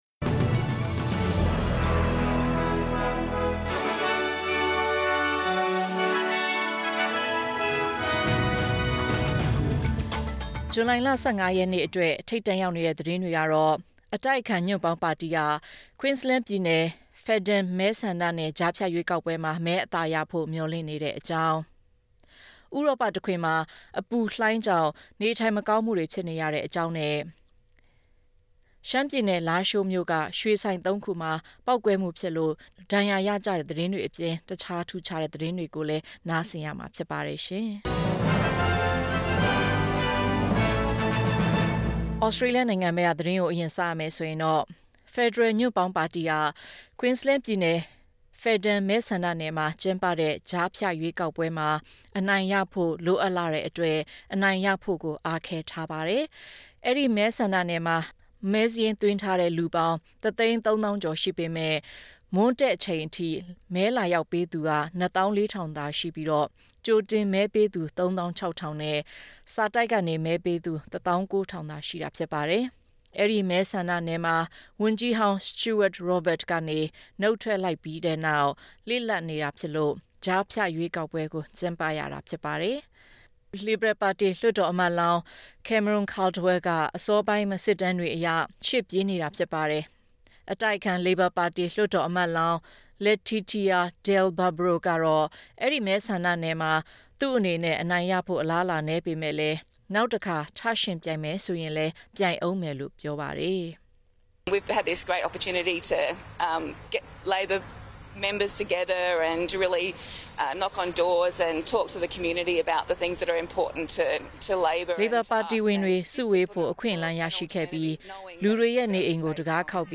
ဇူလိုင် ၁၅ ရက် အတွက် သတင်းများ
burmese-news-15-july-mp3.mp3